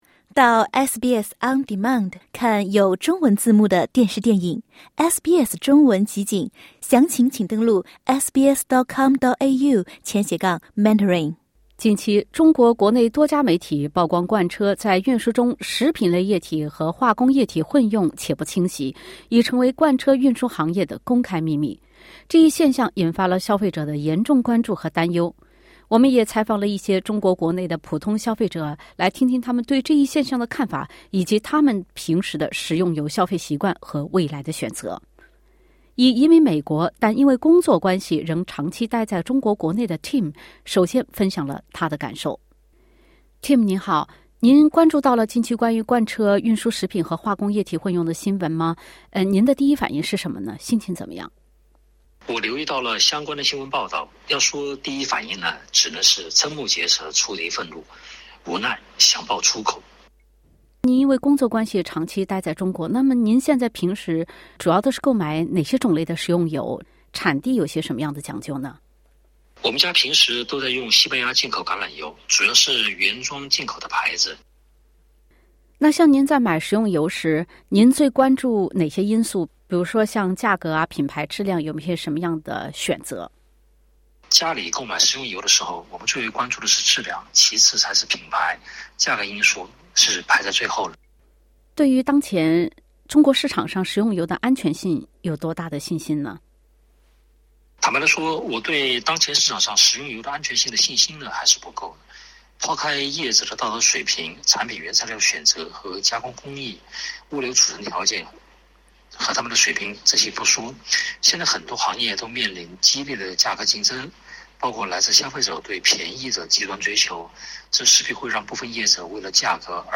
中国国内媒体曝光多家大型粮油企业使用的罐车卸完化工油装食用油引发民众对食用油安全担忧后，两位在中国的消费者分享了他们对此事件的看法，以及他们的食用油消费习惯是否会因此而发生改变。